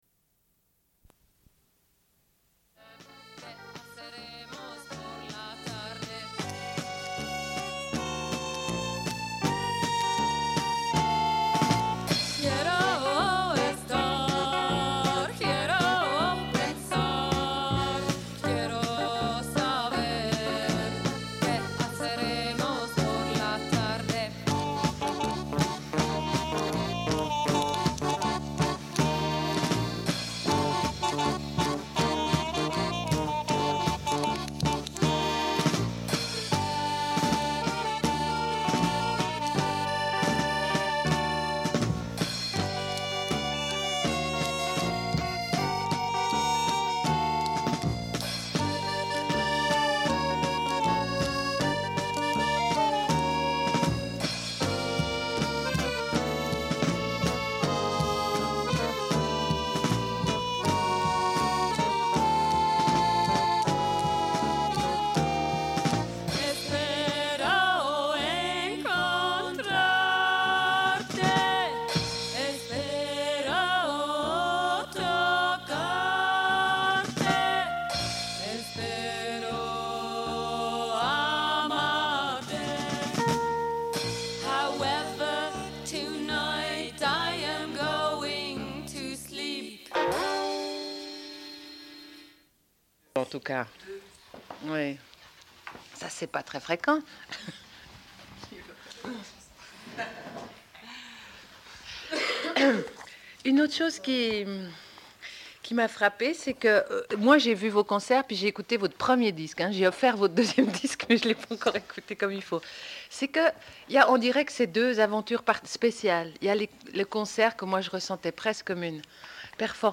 Deuxième partie de l'émission, rediffusion d'un entretien avec les Reines prochaines.